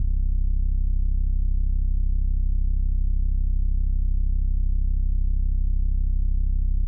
808 motorsport.wav